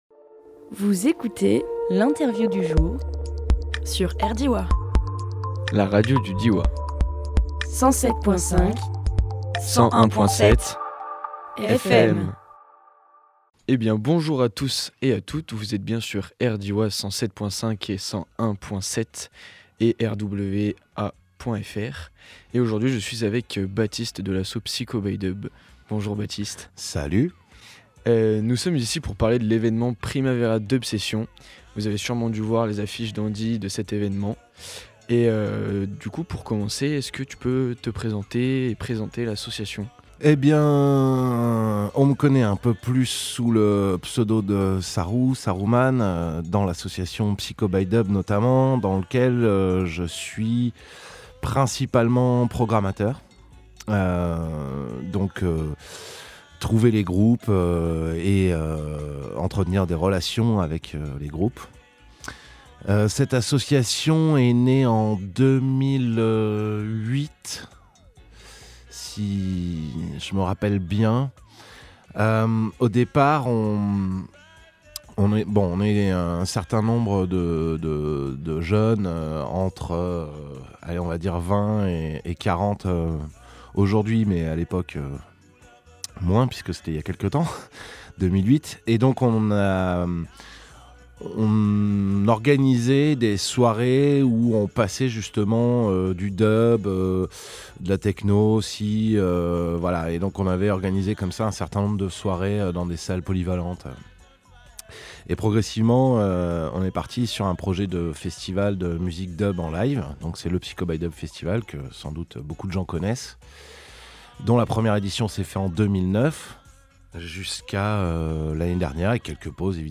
Emission - Interview Primavera DuB Sessione #1 Publié le 28 février 2023 Partager sur… Télécharger en MP3 Pour faire venir le printemps, l’équipe du Psychobydub et le théâtre Les Aires vont faire vibrer les arbres et bourgeonner les consciences à grand renfort de basses le samedi 04 mars à die.